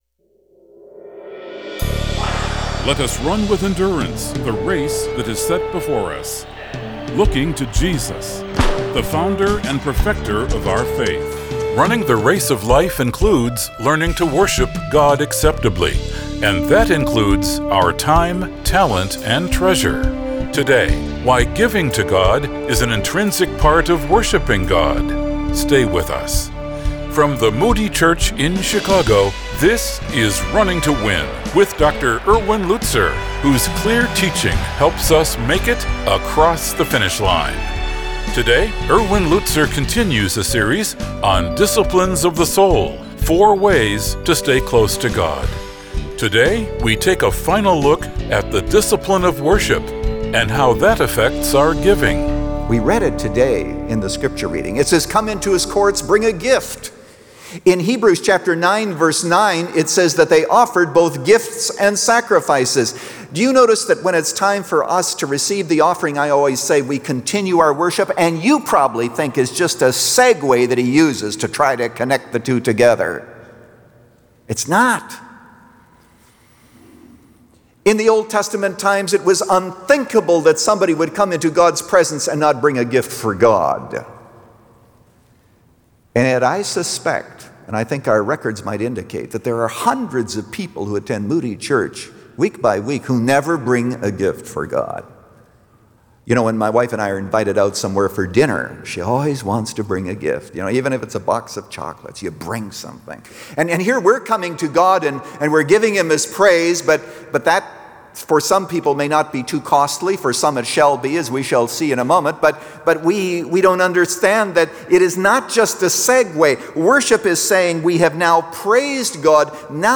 In this message, Pastor Lutzer unpacks two vital lessons about the costliness and importance of worshiping the Lord.
But with the Bible front and center and a heart to encourage, Pastor Erwin Lutzer presents clear Bible teaching, helping you make it across the finish line.